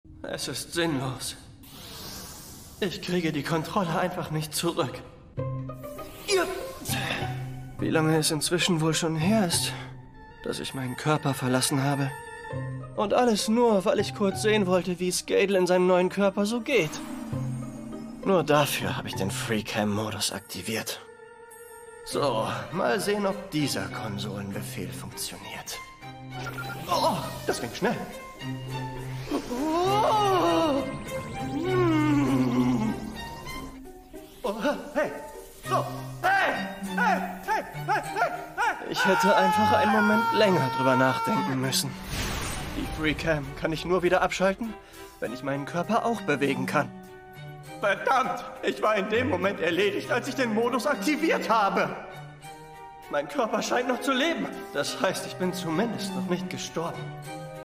hell, fein, zart, markant, sehr variabel
Jung (18-30)
Comment (Kommentar)